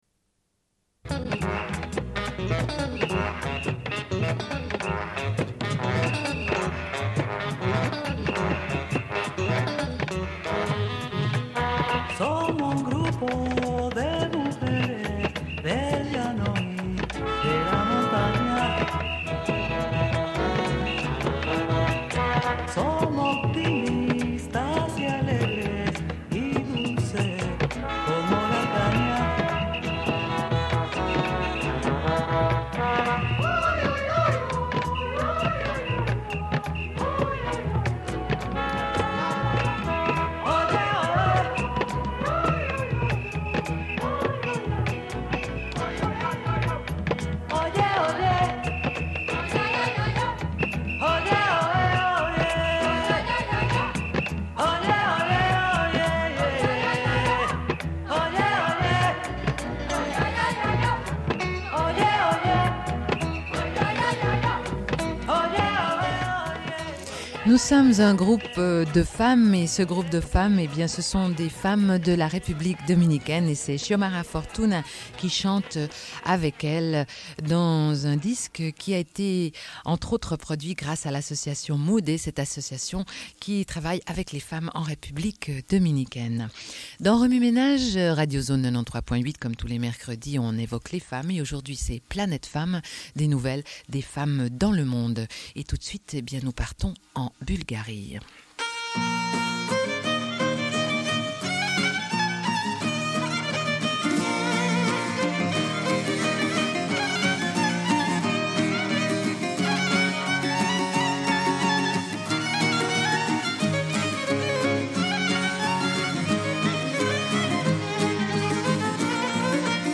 Une cassette audio, face B